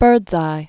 (bûrdzī), Clarence 1886-1956.